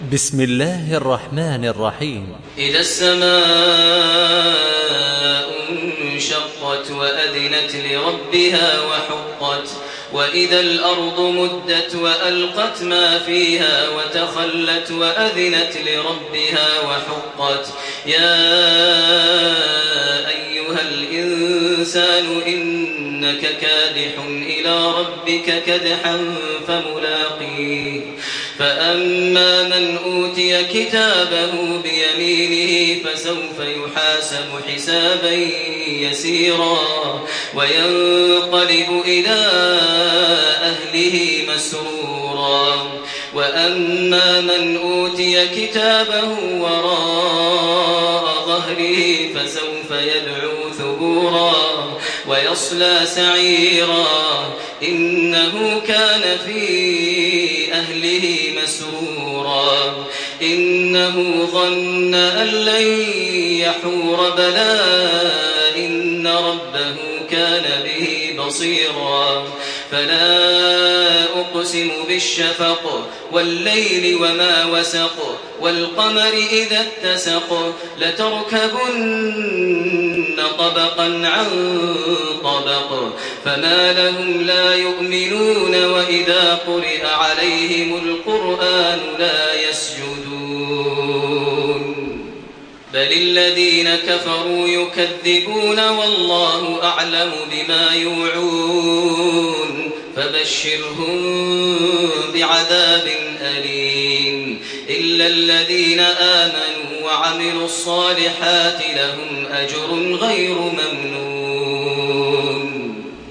سورة الانشقاق MP3 بصوت تراويح الحرم المكي 1428 برواية حفص
تحميل سورة الانشقاق بصوت تراويح الحرم المكي 1428
مرتل